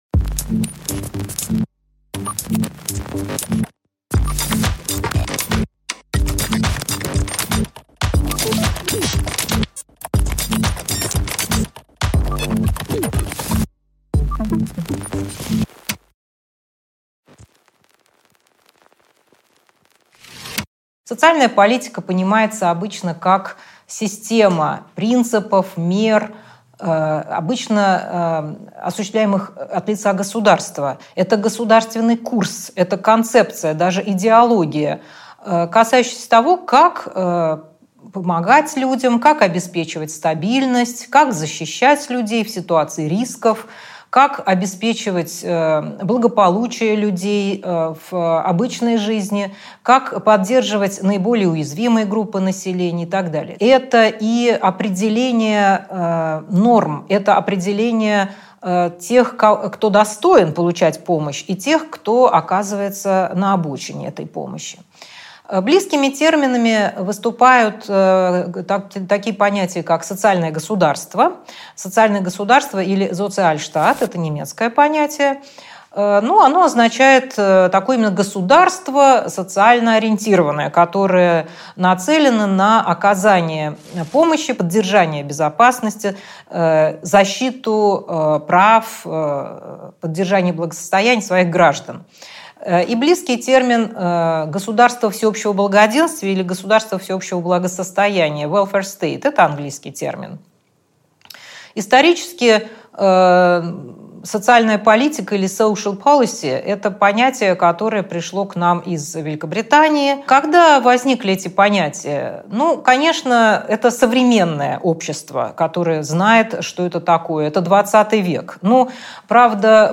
Аудиокнига Для пользы общества | Библиотека аудиокниг